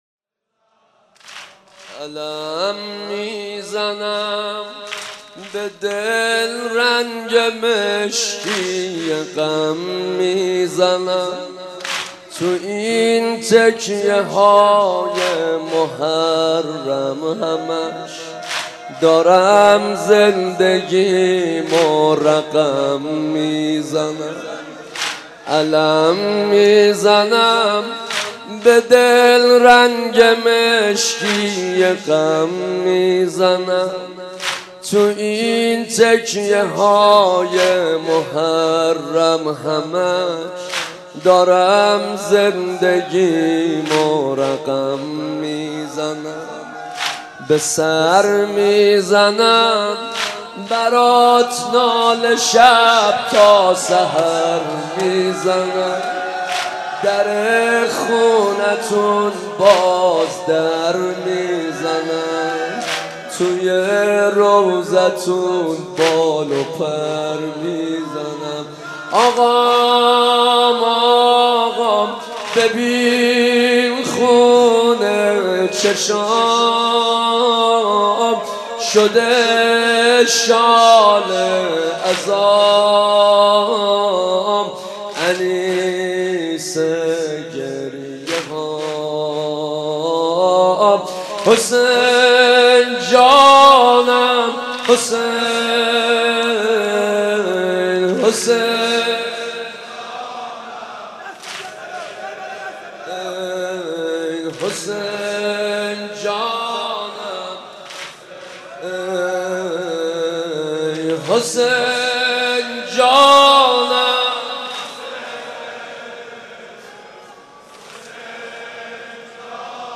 با نوای مداح اهل بیت